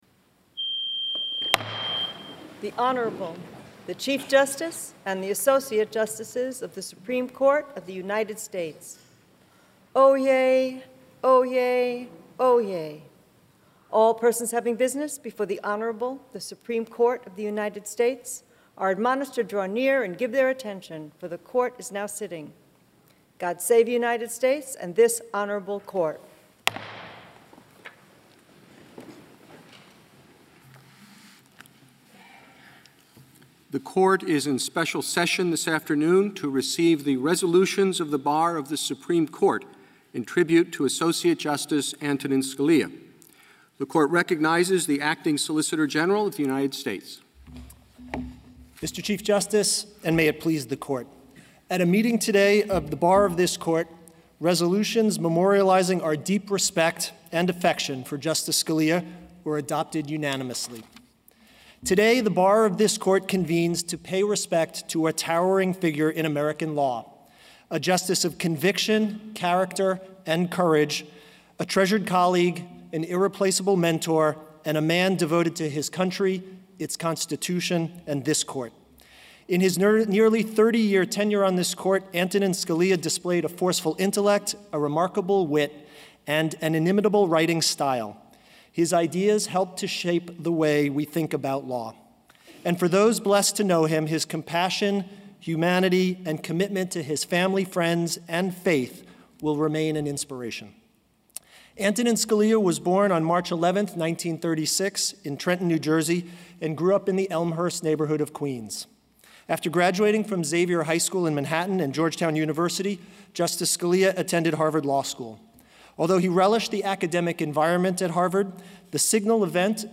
Chief Justice John Roberts did something on Friday that he’s probably never done in open court since he took the bench more than a decade ago: He appeared visibly moved.
The Supreme Court wasn’t hearing any cases, but had gaveled in a special session to memorialize in its public record ― the nation’s public record ― the death of Justice Antonin Scalia.